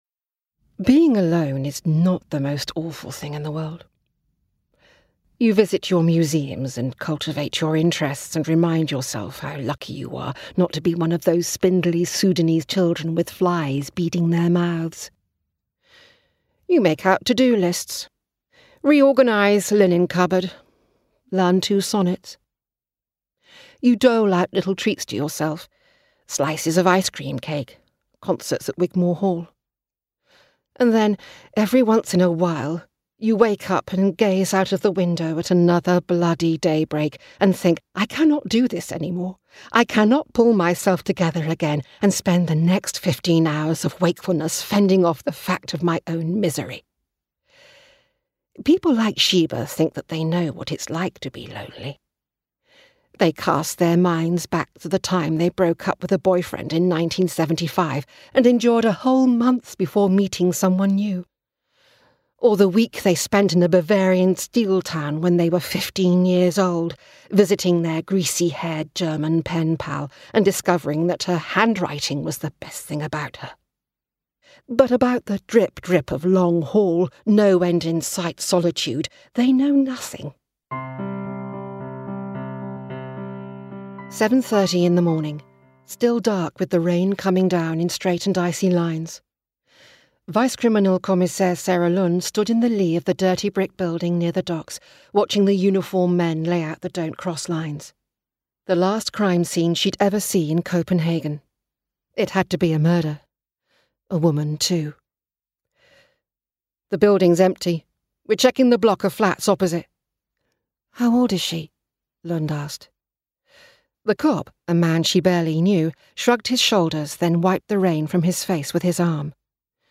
Female
Assured, Engaging, Reassuring, Smooth, Warm
RP - West Country (Gloucestershire - Native) - Southern Irish - Welsh - American English -
Microphone: Sennheiser MK4 - Sennheiser MKH416